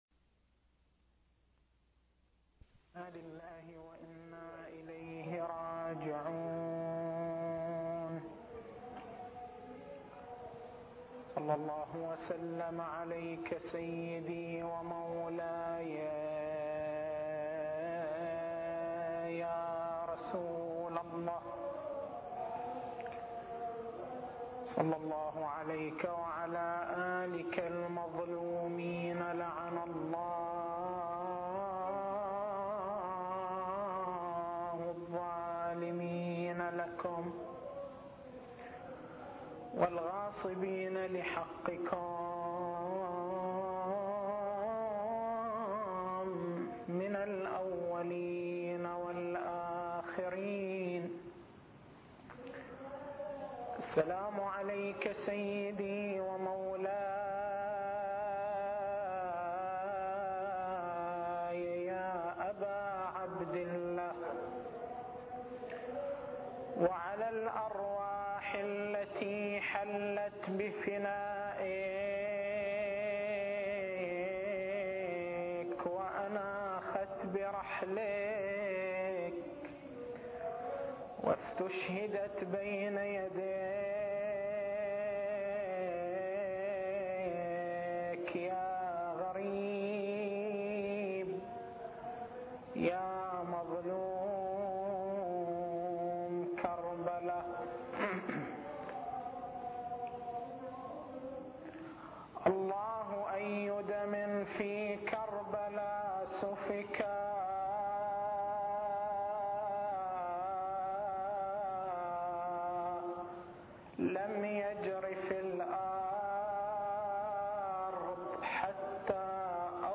تاريخ المحاضرة: 01/01/1422 نقاط البحث: أهمية المنبر الحسيني أدوار المنبر الحسيني وظيفتنا تجاه المنبر الحسيني التسجيل الصوتي: تحميل التسجيل الصوتي: شبكة الضياء > مكتبة المحاضرات > محرم الحرام > محرم الحرام 1422